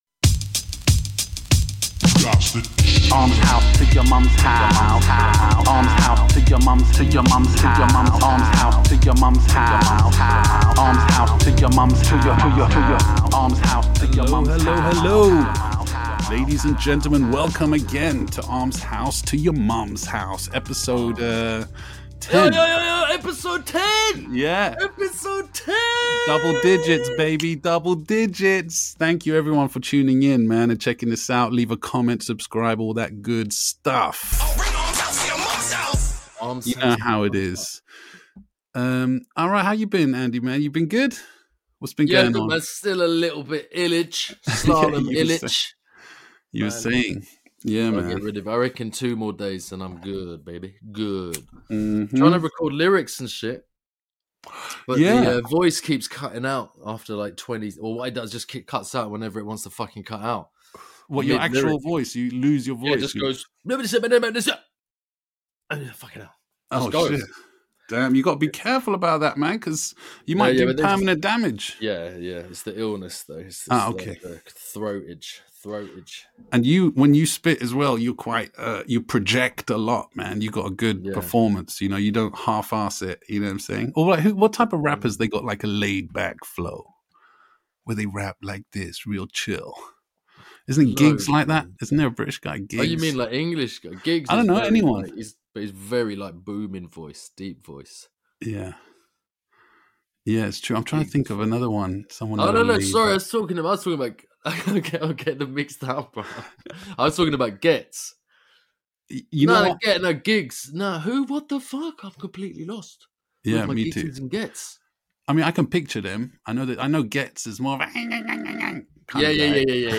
This week the lads play another round of the Graff word association game, tell more 'naughty boy' stories from school days, their favourite Graff Crew meanings and listen to some tunes!